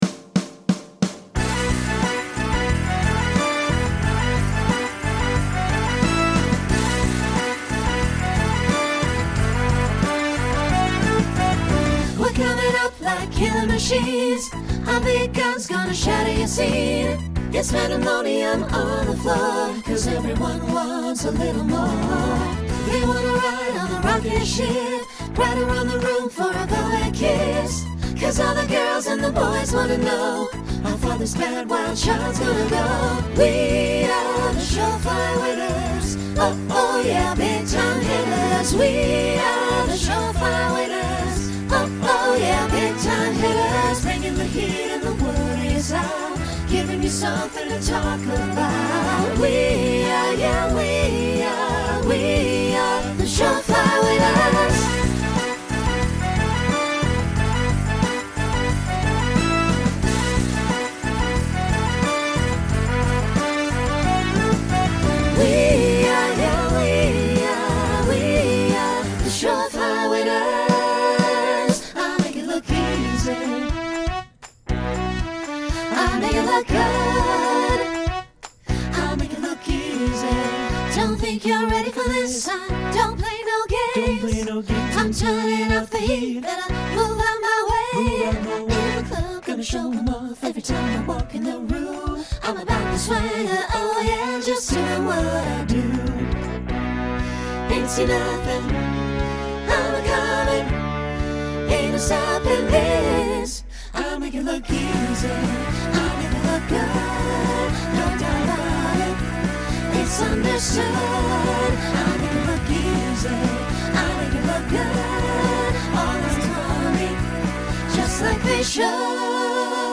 Genre Rock Instrumental combo
Voicing SATB